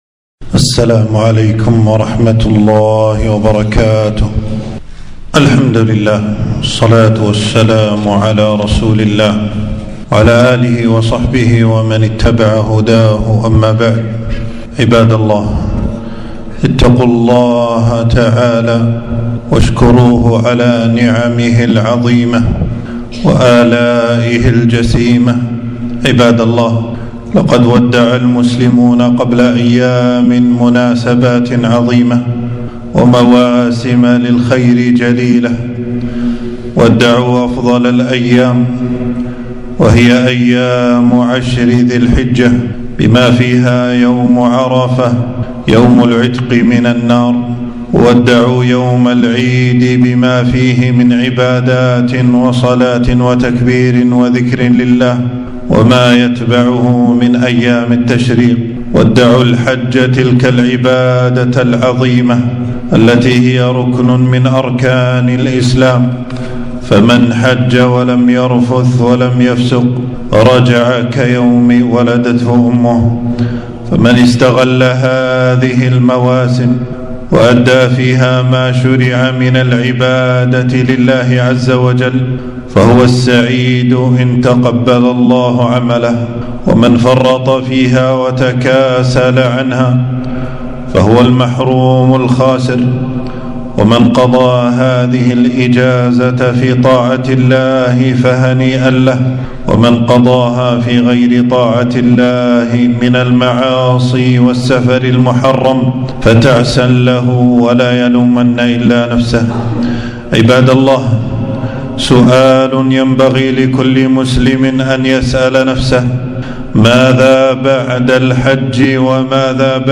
خطبة - ( واعبد ربك حتى حتى يأتيك اليقين )